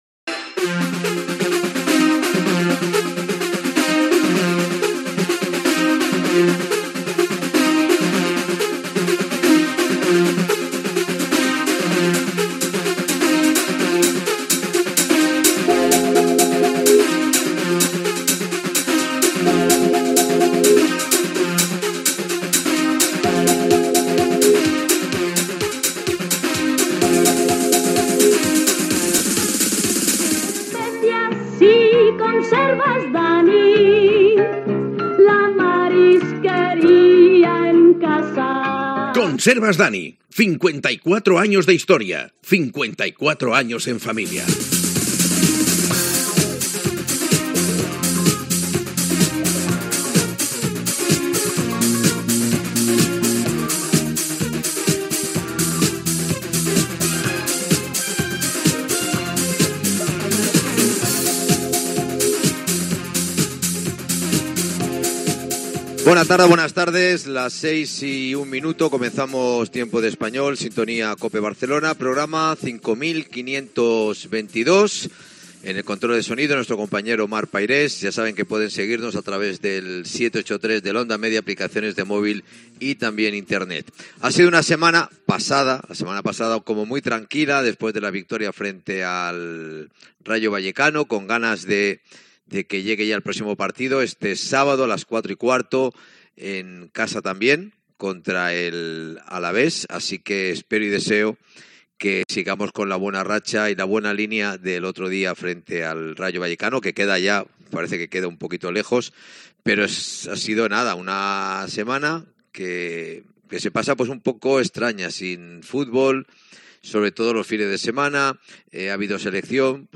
Sintonia, publicitat, informació relacionada amb el Real Club Deportivo Español.
Esportiu